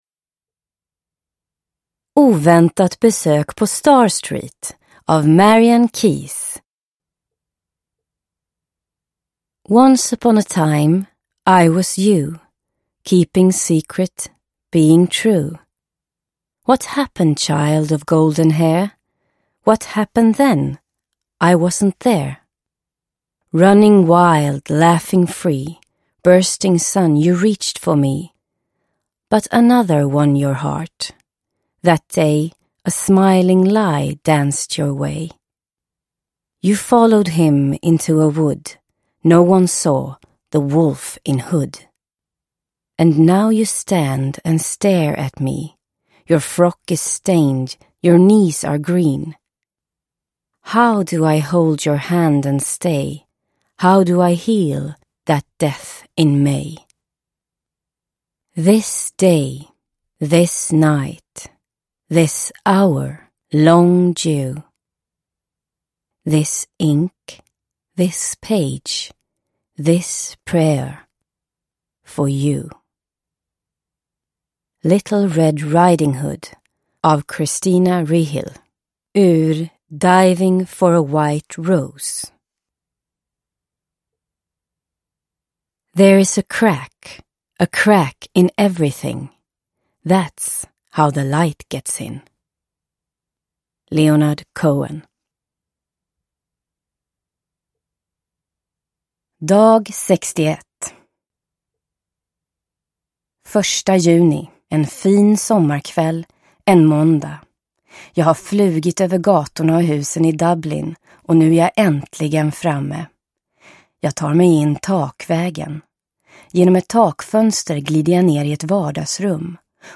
Oväntat besök på Star Street – Ljudbok – Laddas ner